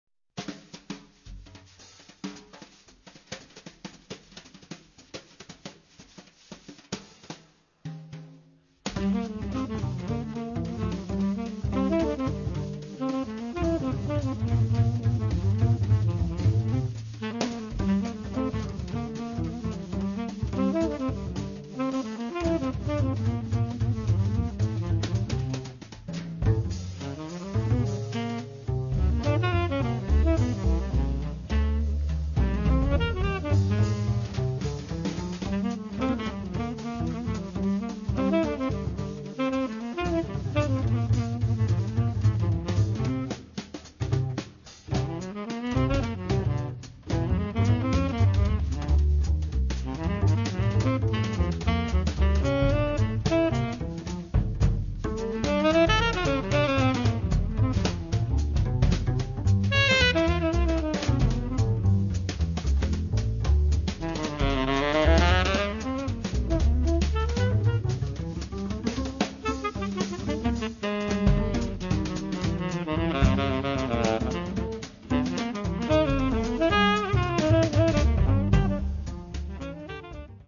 tenor saxophone
bass
drums